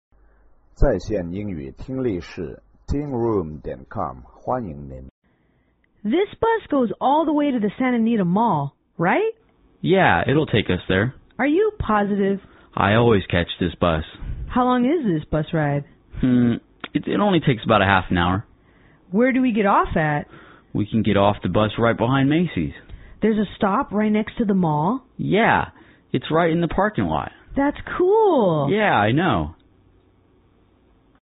乘车英语对话-Asking about Where to Get Off(1) 听力文件下载—在线英语听力室